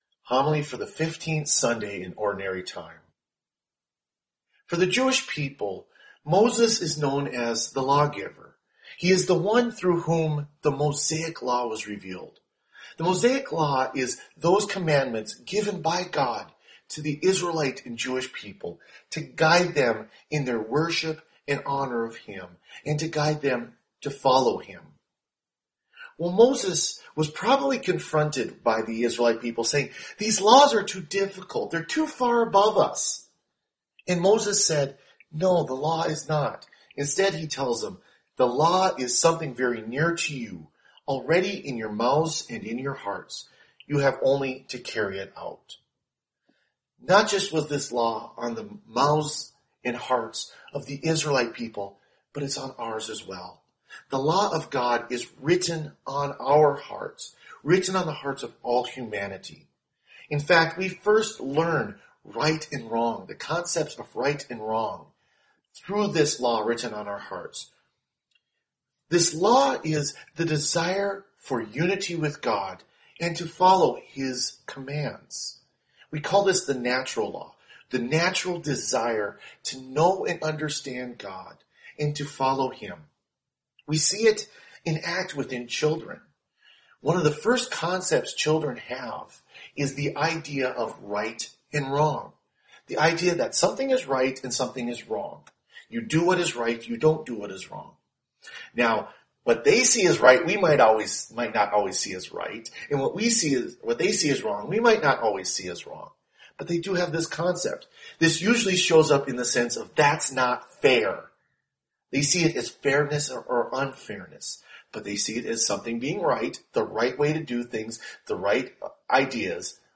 Homily for the Fifteenth Sunday in Ordinary Time